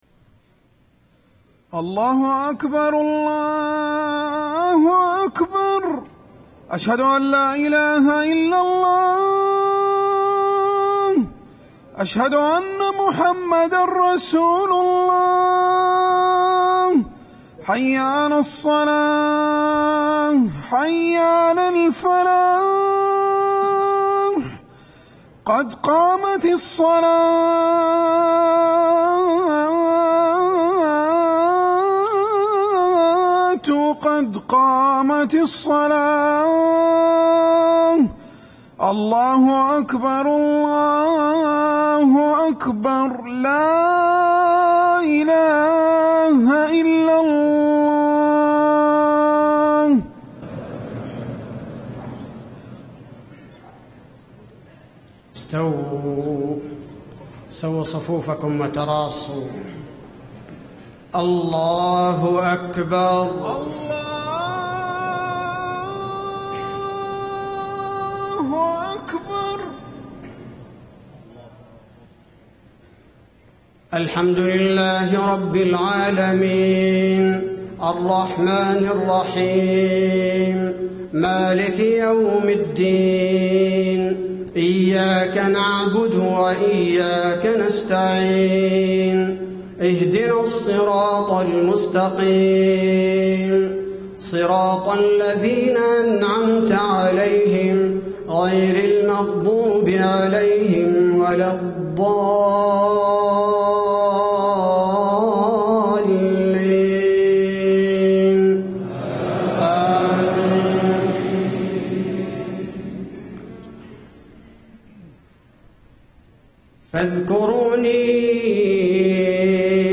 صلاة الجمعة 3 - 7 - 1435هـ من سورة البقرة و سورة الكوثر > 1435 🕌 > الفروض - تلاوات الحرمين